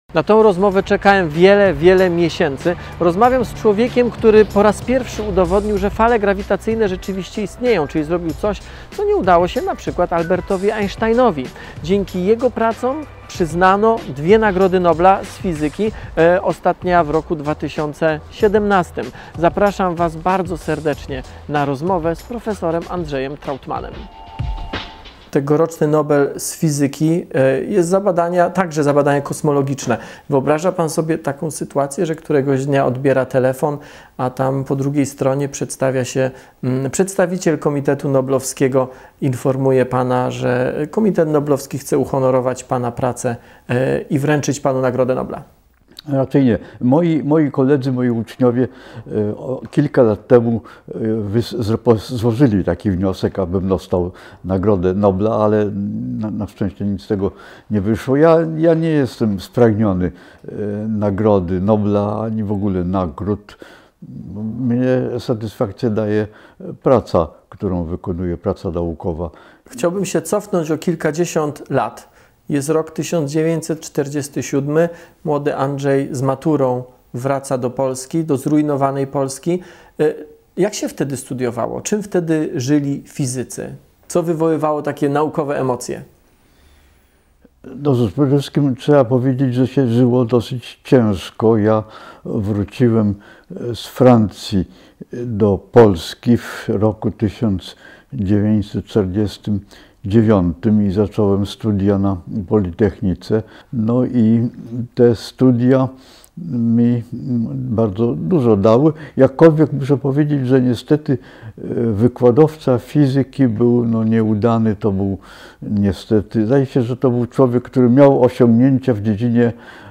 To on udowodnił istnienie fal grawitacyjnych, dzięki jego pracom przyznano dwie Nagrody Nobla. Wywiad przeprowadził Tomasz Rożek w styczniu 2020 roku. 27 lutego 2026 roku odszedł człowiek, który nauczył nas słyszeć Wszechświat.